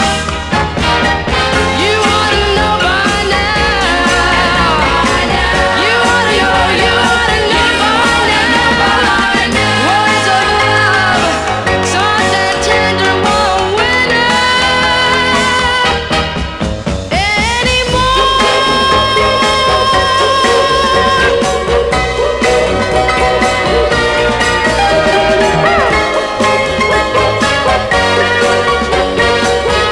Жанр: Фолк-рок / Рок